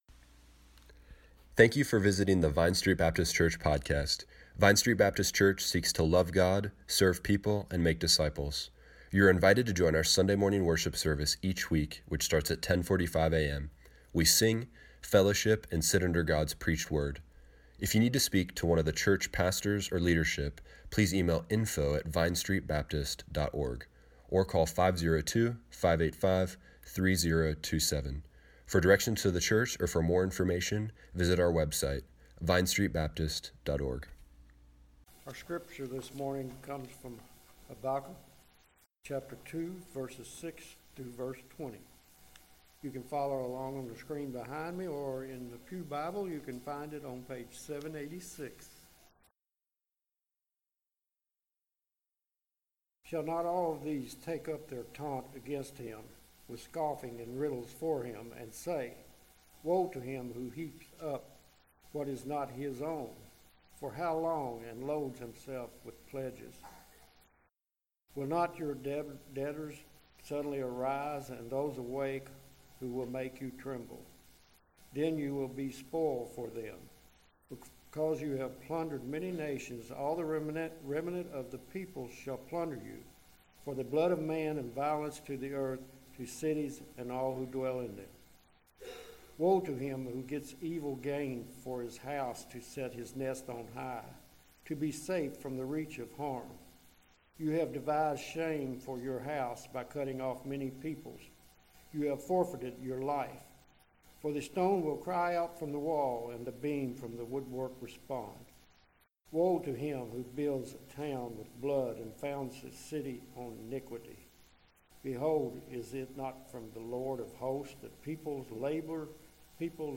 Service Morning Worship Tweet Summary March 1, 2020 God gives Habakkuk perspective on the proud Chaldeans, and all who oppose God. Five woes against the proud.